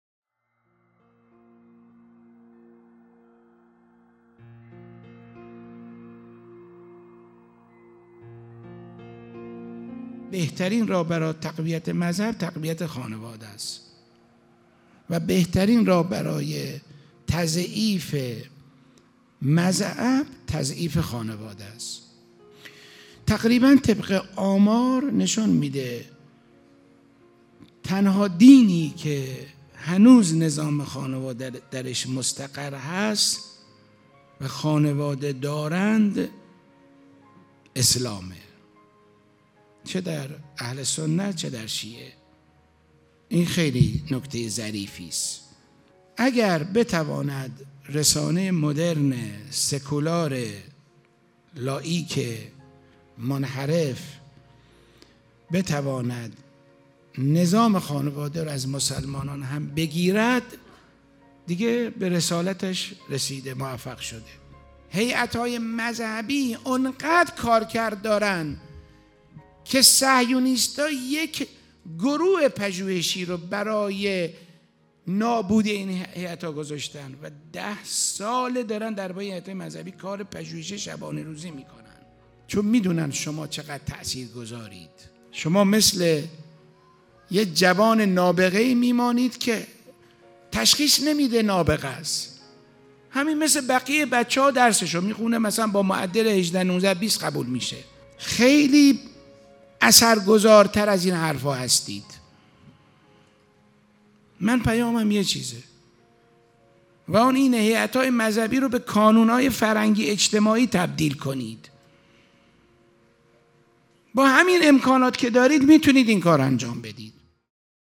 یازدهمین همایش هیأت‌های محوری و برگزیده کشور | شهر مقدس قم - مسجد مقدس جمکران - مجتمع یاوران مهدی (عج)